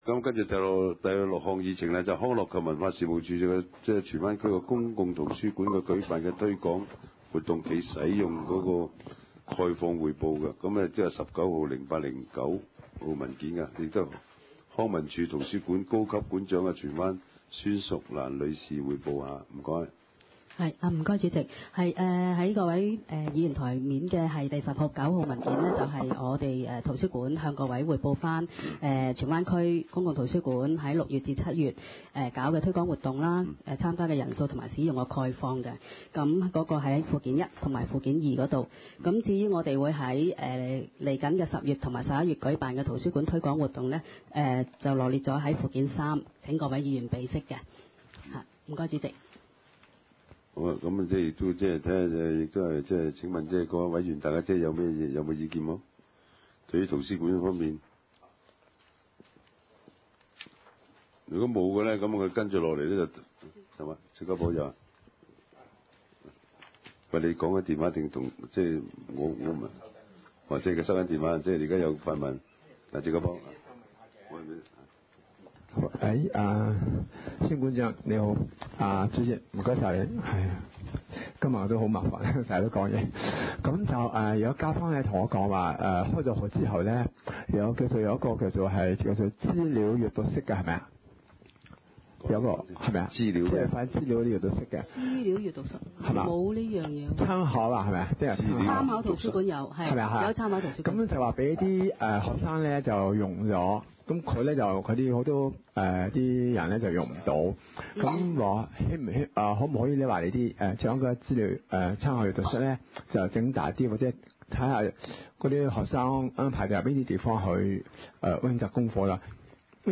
地區設施管理委員會第五次會議
荃灣民政事務處會議廳